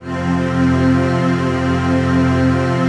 CHRDPAD028-LR.wav